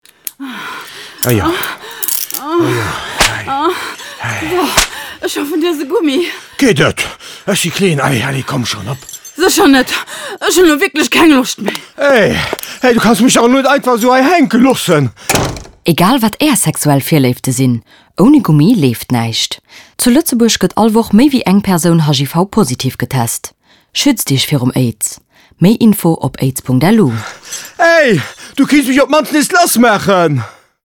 spot-radio-sida-tue-toujours.mp3